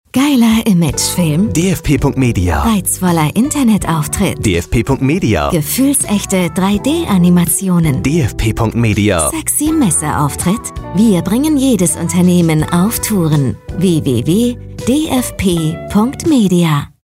Funkspot.mp3